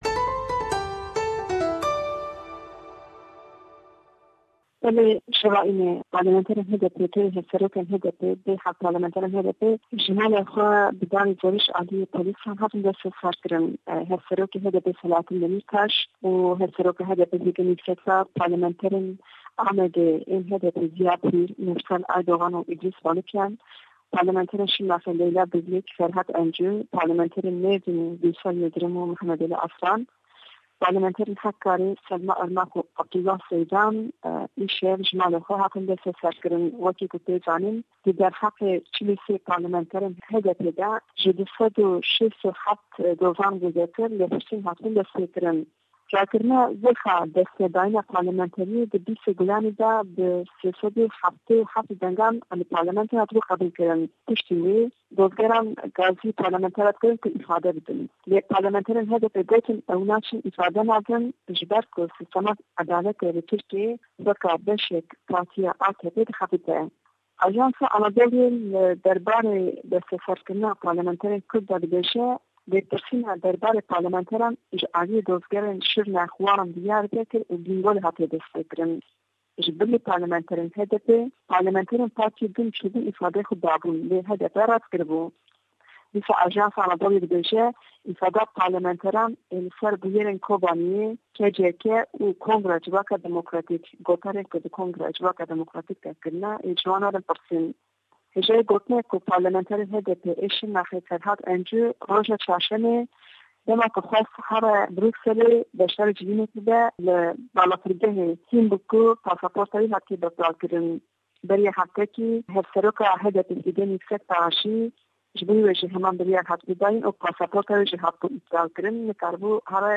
neytwanî raportî xoyman bo binêrêt, boye be telefon diwa hewallî pê rageyandîn.